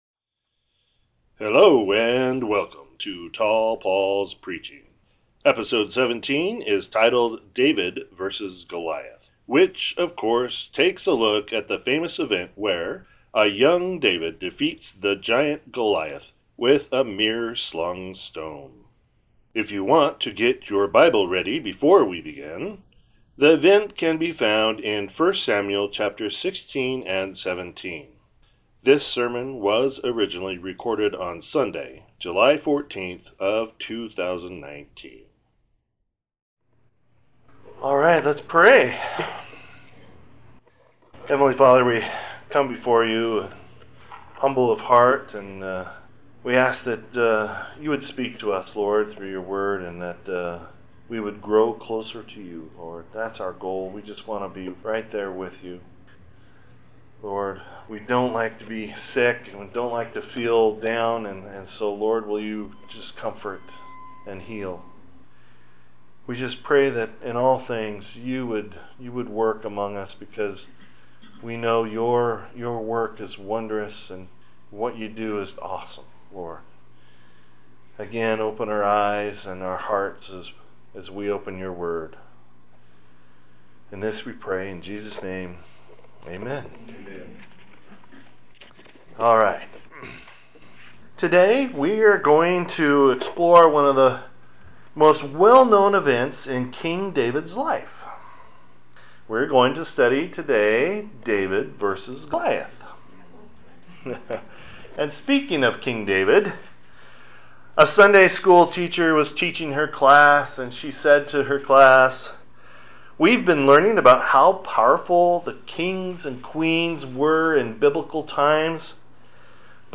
This sermon was originally recorded on Sunday July 14th, 2019.